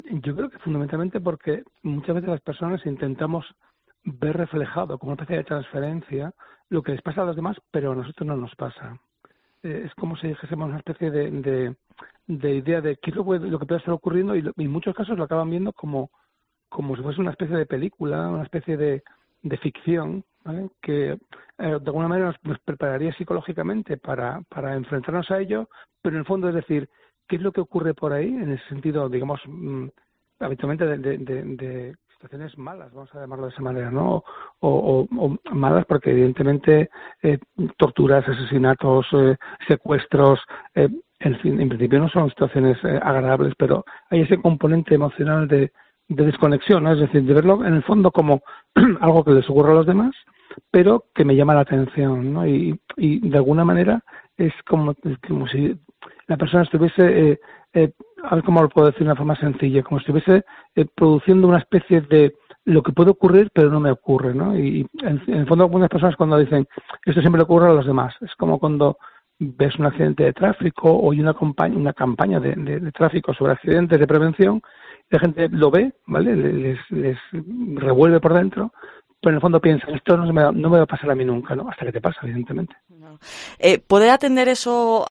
Un profesor de Psicología nos explica la razón por la que triunfan los documentales y pódcast de true crime